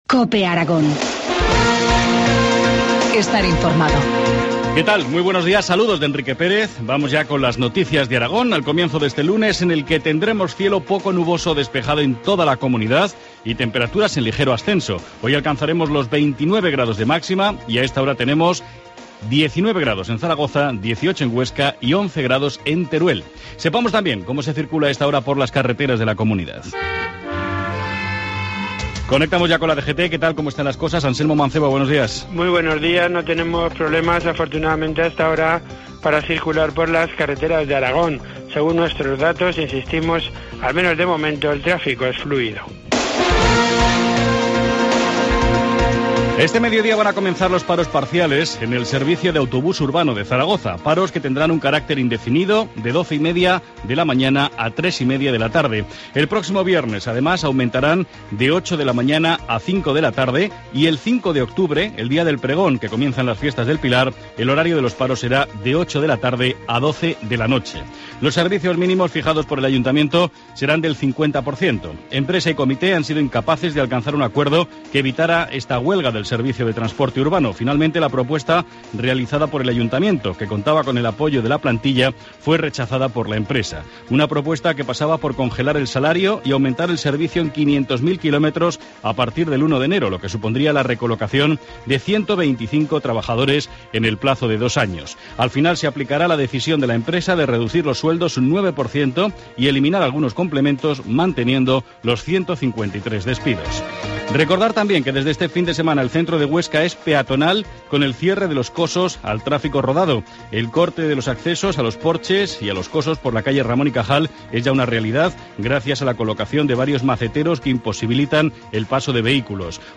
Informativo matinal, lunes 23 de septiembre, 7.25 horas